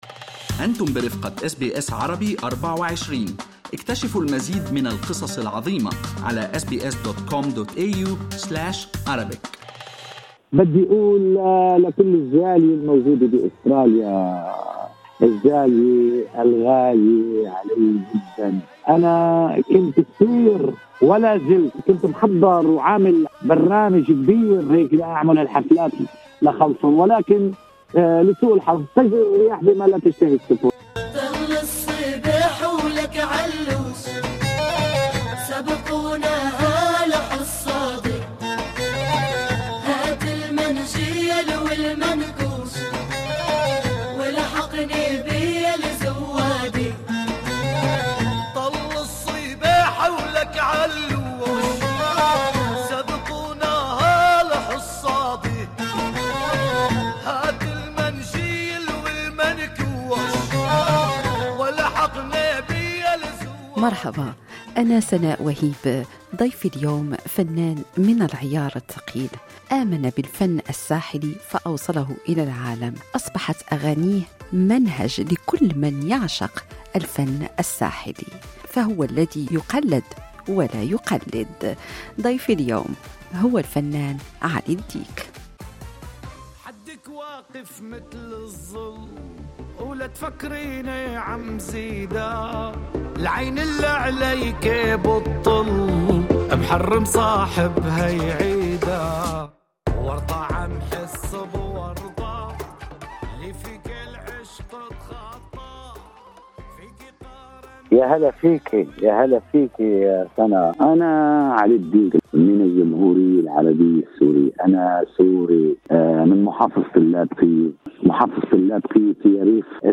في لقاء حصري لبنامج بيت المزيكا النجم علي الديك يعلن ولأول مرة عن مشروعه الجديد.